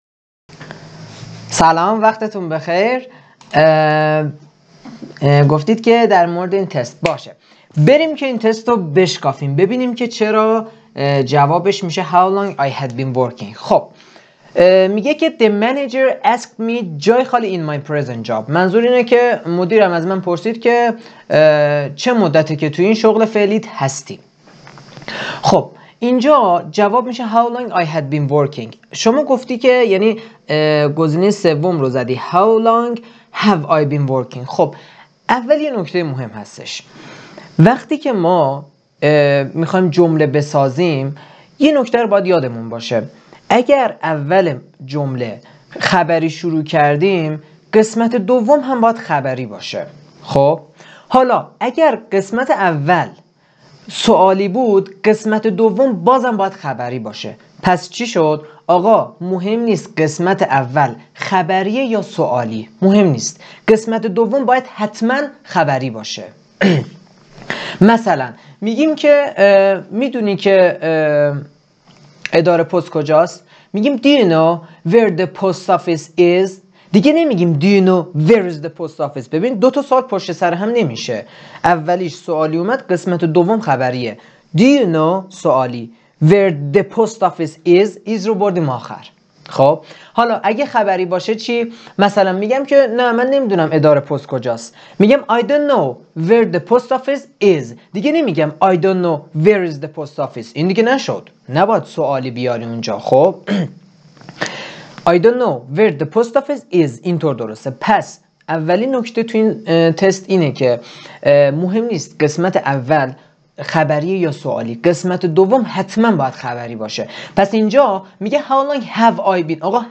صداتون برای توضیح مطالب بسیار هیجان انگیز و بسیار عالی بود.